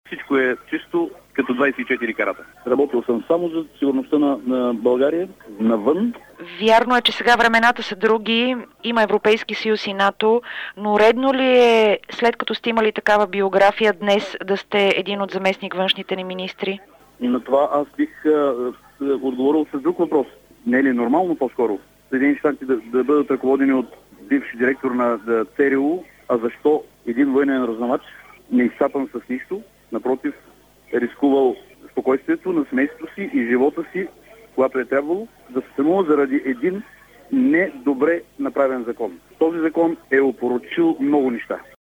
Коментар на зам.-външния министър Радион Попов